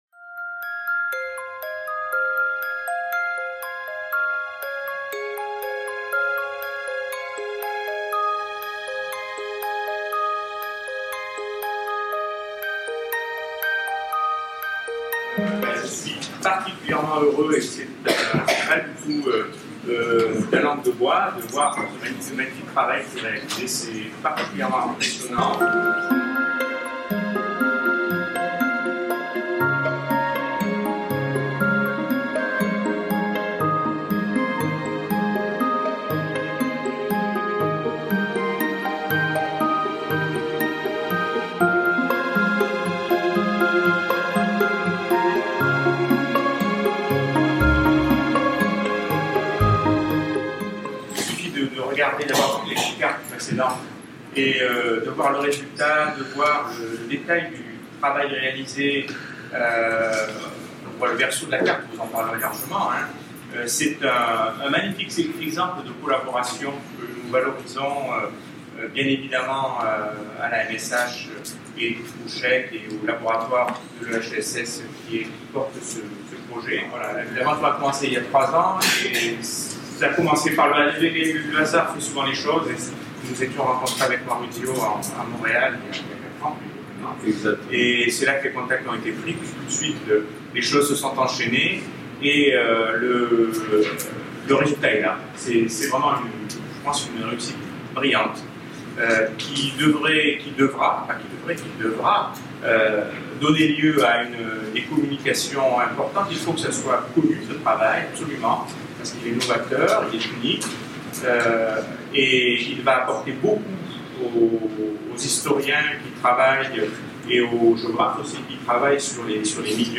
Introduction du Séminaire Atlas historique (CHEC, UCA) GeoHistoricaldata (CRH, EHESS) consacré à l'édition et la valorisation de la feuille 52 de la carte de Cassini (Clermont).